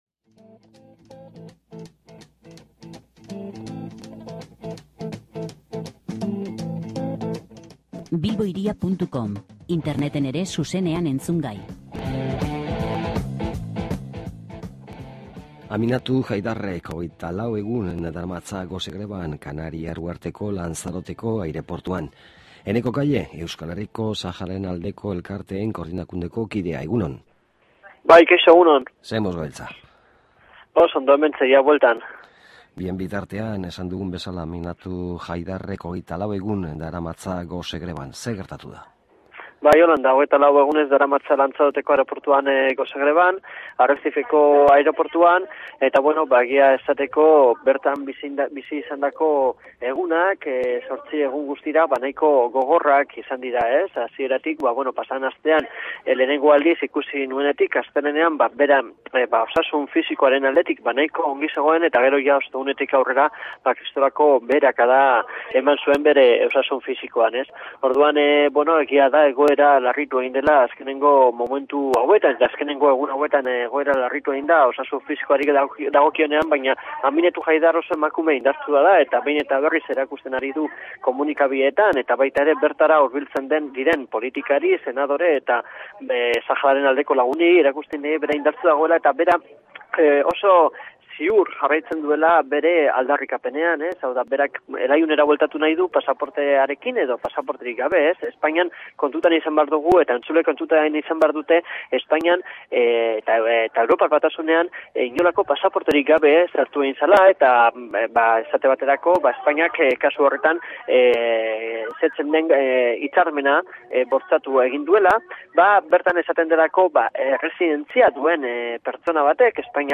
solasaldia